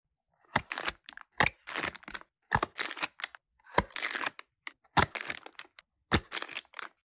MIXO 40000 Unboxing ASMR 😋✨ sound effects free download